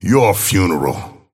Abrams voice line - Your funeral.